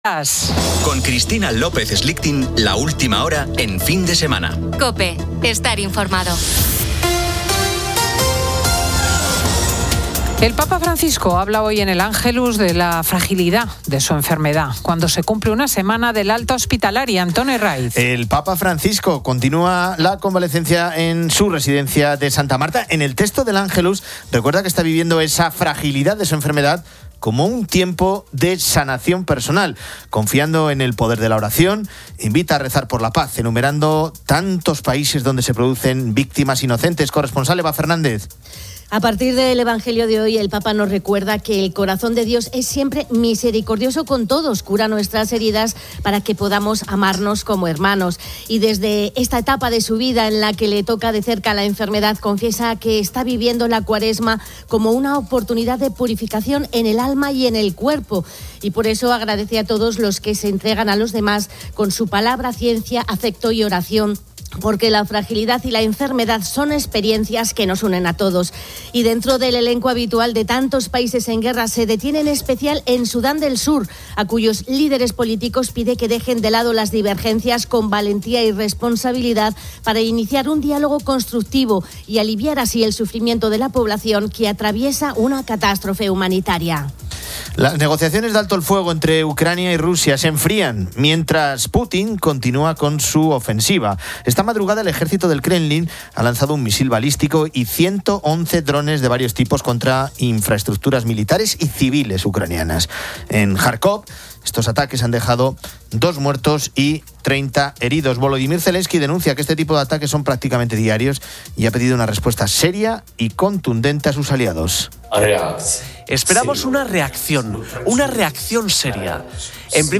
Tertulia saludable sobre alimentos que alargan la vida y hábitos para ser felices